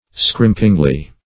scrimpingly - definition of scrimpingly - synonyms, pronunciation, spelling from Free Dictionary Search Result for " scrimpingly" : The Collaborative International Dictionary of English v.0.48: Scrimpingly \Scrimp"ing*ly\, adv. In a scrimping manner.
scrimpingly.mp3